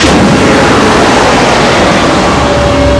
Pictures: Entrata hangar Portellone esterno hangar Uscita dall'hangar Hangar navette Media: Decompressione hangar References: